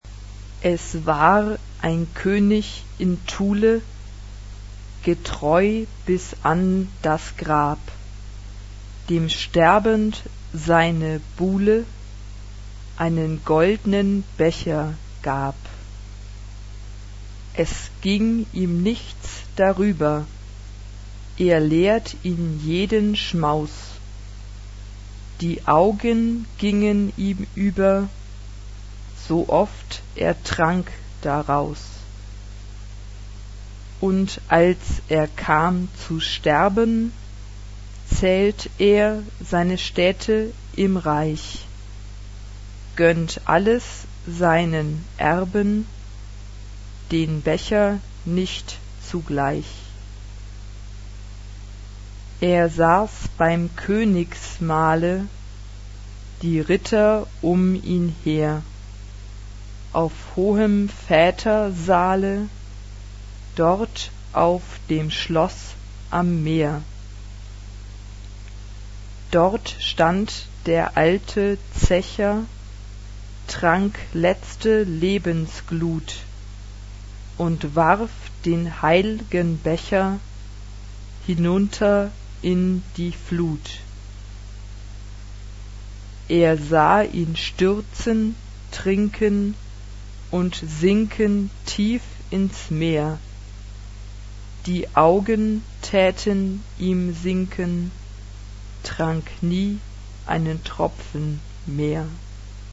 SSATB (5 voix mixtes).
Romantique.
Caractère de la pièce : lent
Tonalité : la mineur